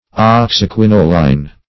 Search Result for " oxyquinoline" : The Collaborative International Dictionary of English v.0.48: Oxyquinoline \Ox`y*quin"o*line\, n. [Oxy (b) + quinoline.]
oxyquinoline.mp3